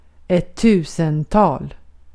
Uttal
IPA : /mɪˈlɛnɪəm/